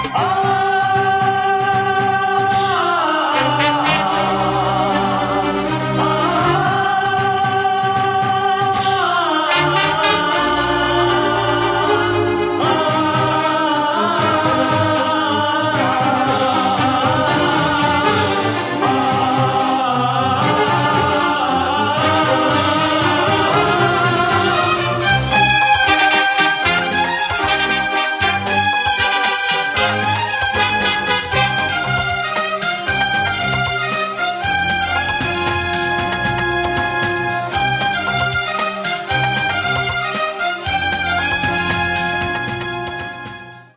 Interludes